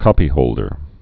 (kŏpē-hōldər)